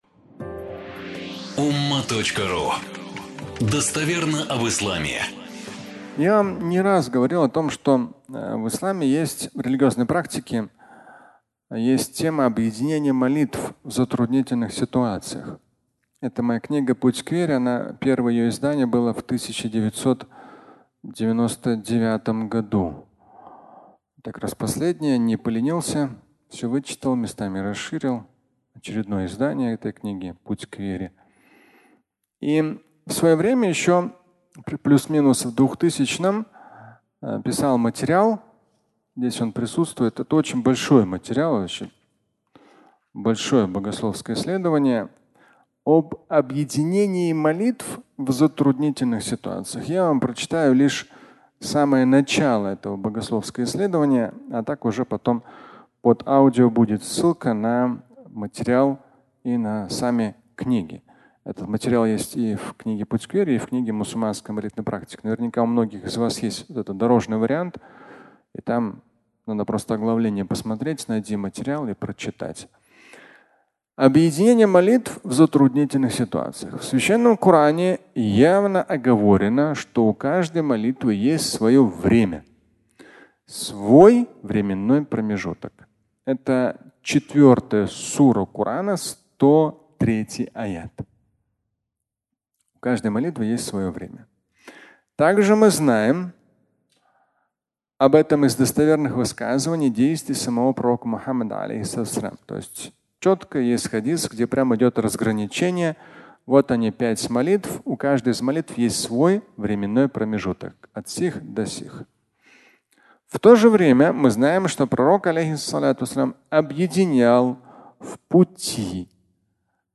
Объединение молитв (аудиолекция)
Пятничная проповедь